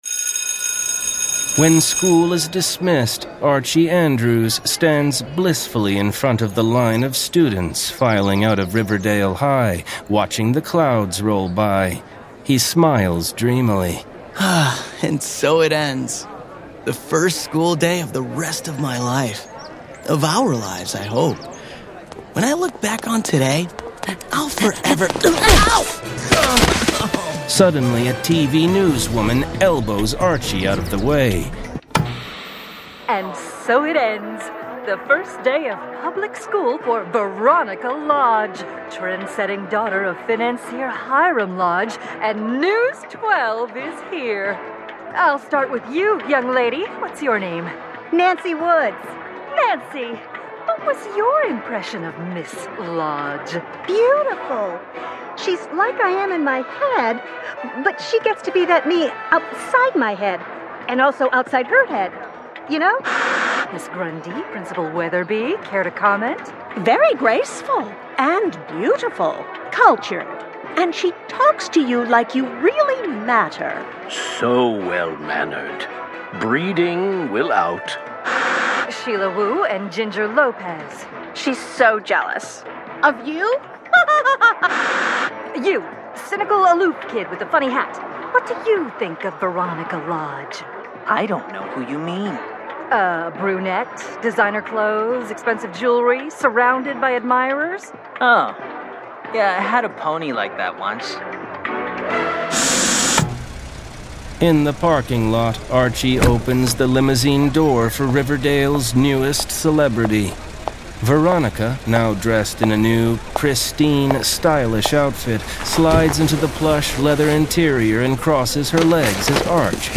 Full Cast. Cinematic Music. Sound Effects.
[Dramatized Adaptation]
Adapted from Archie issues #1–6 and produced with a full cast of actors, immersive sound effects and cinematic music.